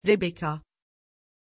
Pronunția numelui Rebeka
pronunciation-rebeka-tr.mp3